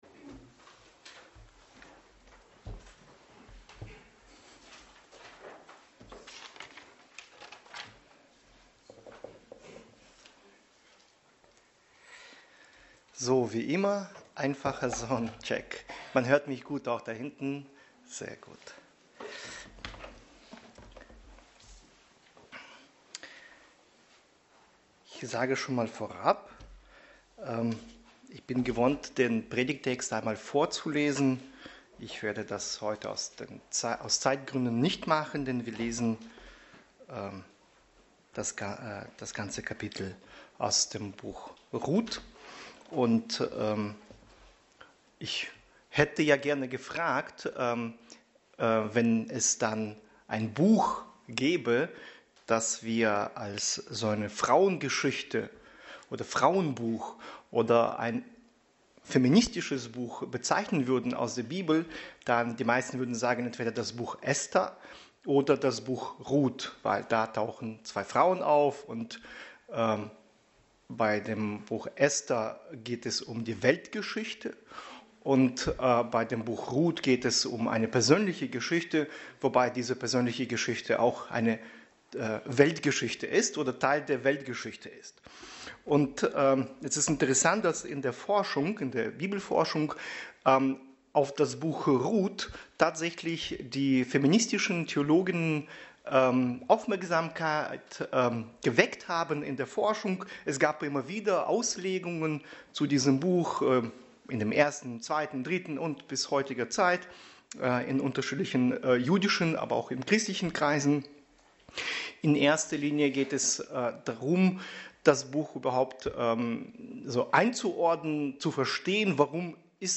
Passage: Ruth 1 Dienstart: Predigt